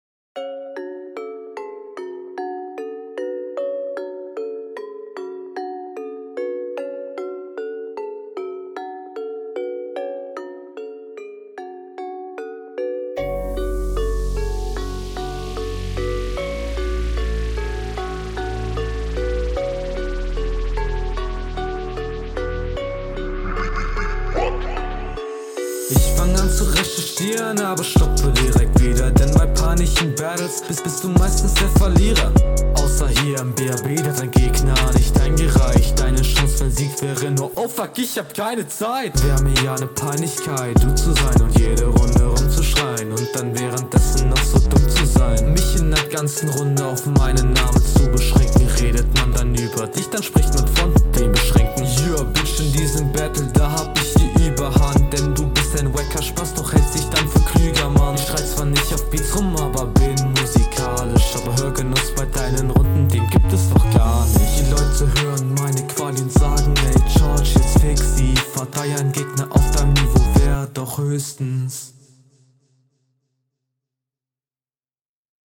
Flow: der flow ist sehr gut und passt super zum beat Text: der keine zeit …
Flow: Flow sehr cool, aber manchmal hört es sich so an, als wäre der Text …